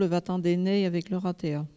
Localisation Bois-de-Céné
Langue Maraîchin
Catégorie Locution